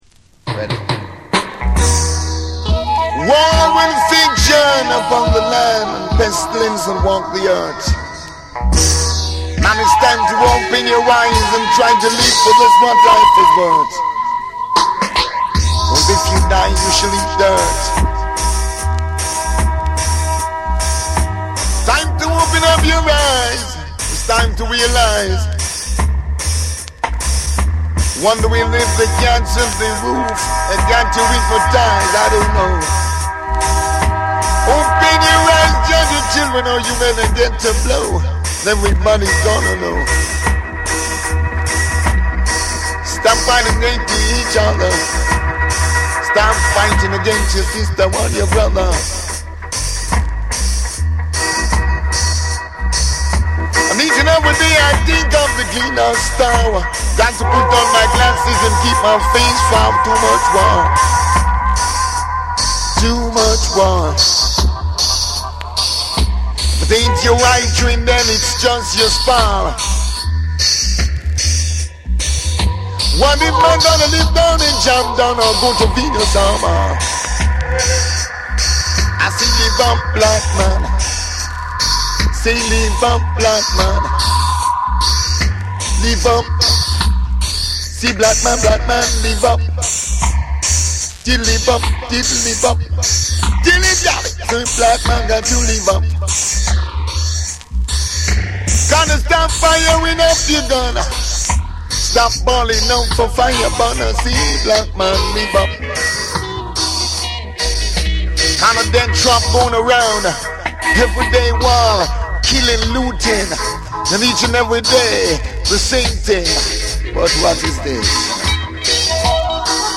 ルーツ期のDJスタイルとダブの黄金コンビネーションが味わえる必聴の一枚。
REGGAE & DUB